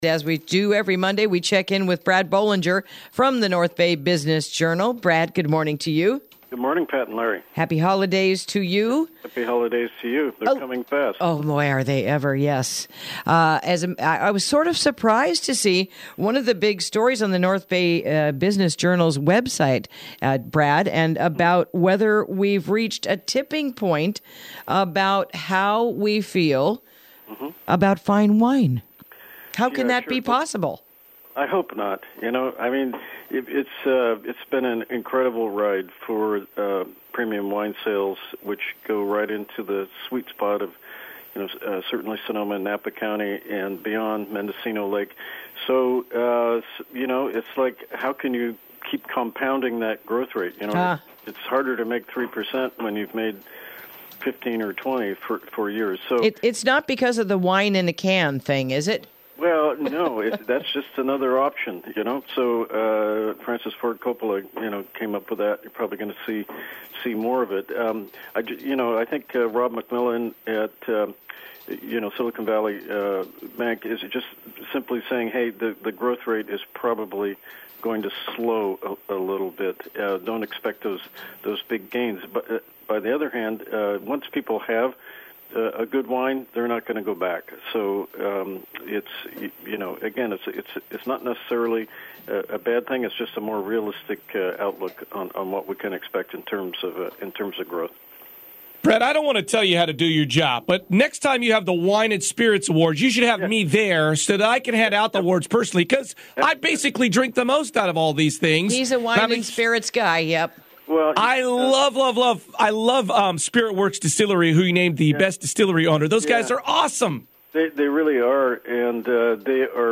Interview: Takin’ Care of Business